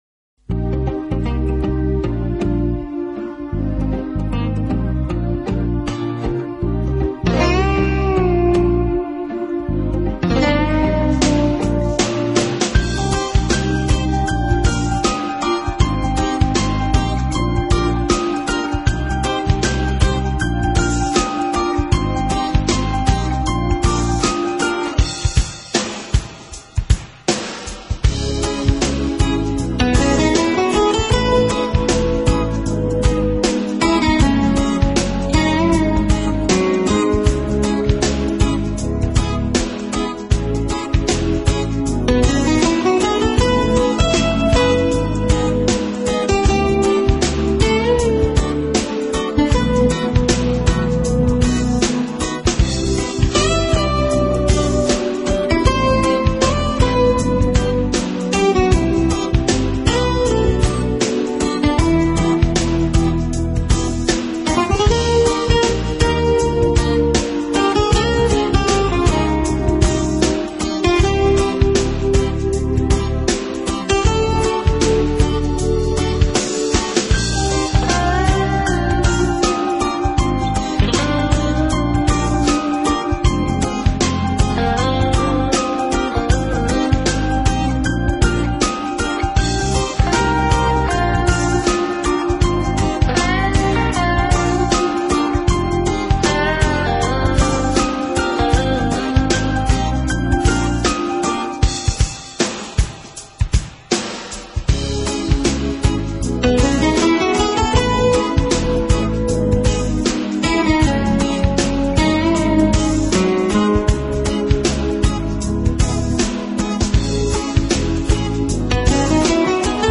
英国双人吉他组合
音乐类型：Jazz 爵士
音乐风格：Smooth Jazz，Contemporary，Instrumental